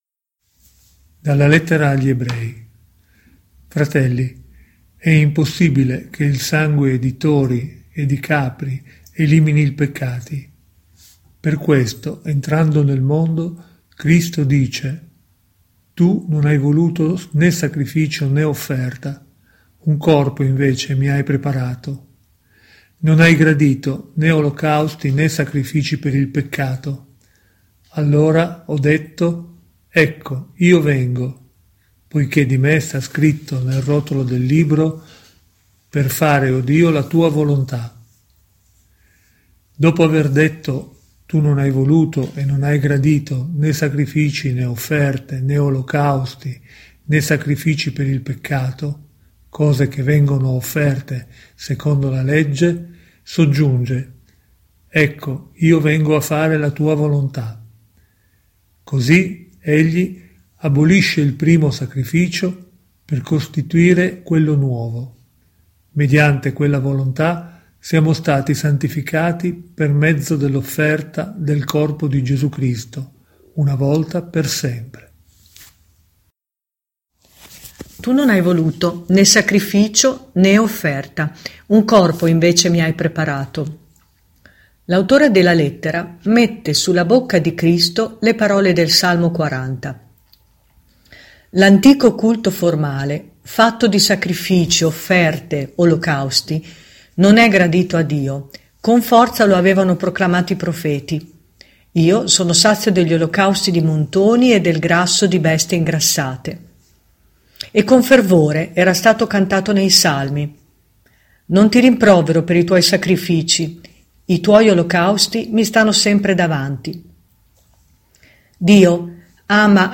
Voci diverse si alternano per farci ascoltare la Parola di Dio, balsamo per i nostri cuori, in questi giorni di emergenza.
La lettura e il commento